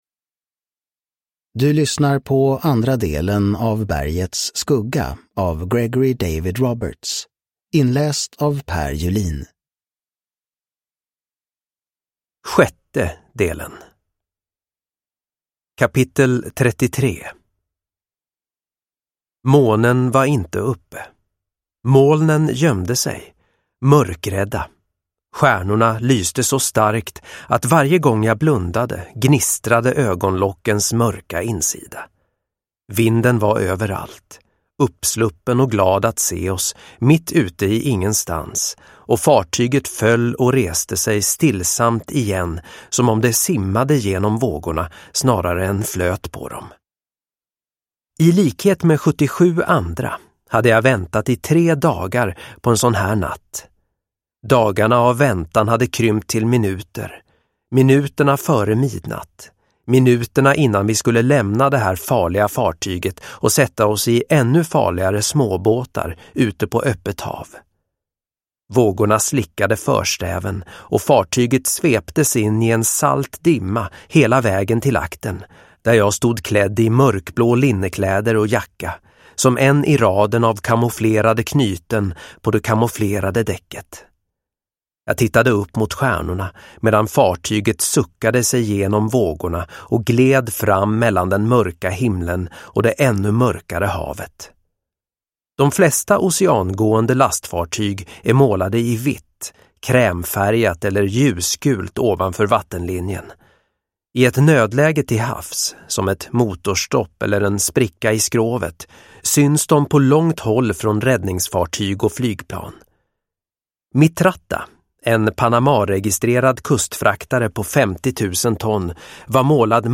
Bergets skugga. Del 2 – Ljudbok – Laddas ner